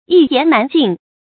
注音：ㄧ ㄧㄢˊ ㄣㄢˊ ㄐㄧㄣˋ
一言難盡的讀法